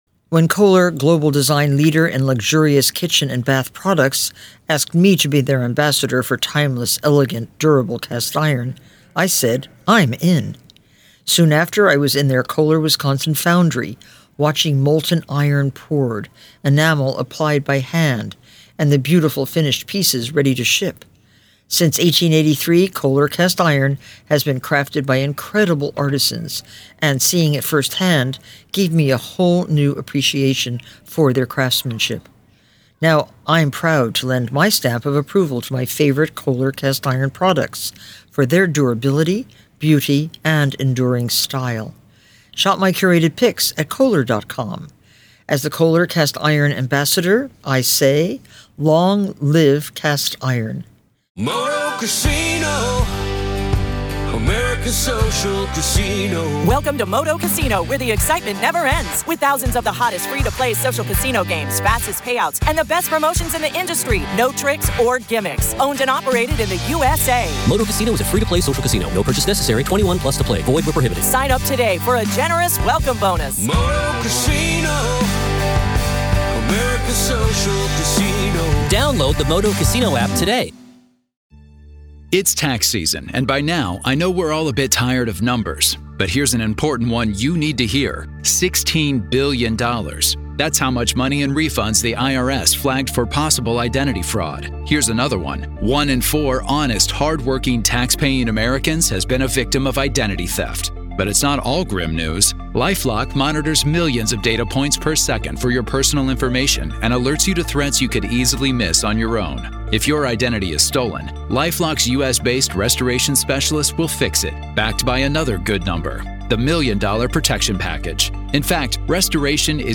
Tune in to hear this insightful discussion and get a closer look into the strategic maneuvers often deployed in the legal arena.